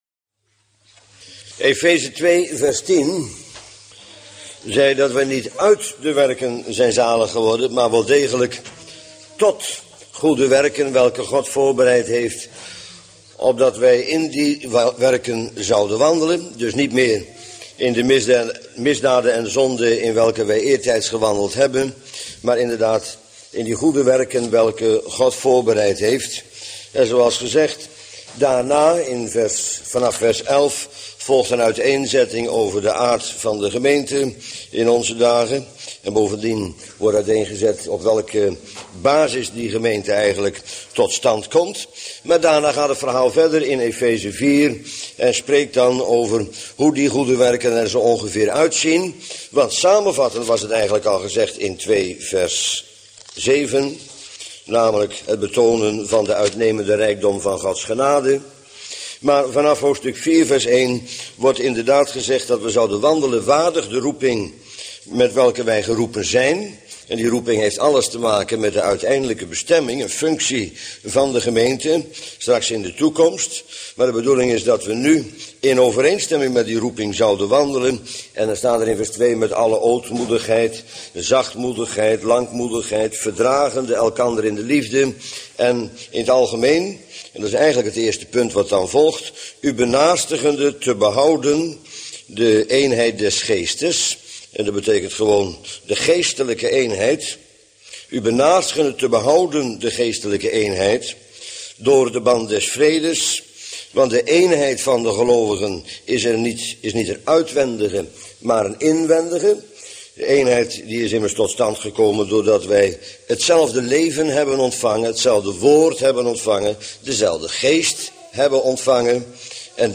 Bijbelstudie lezingen mp3.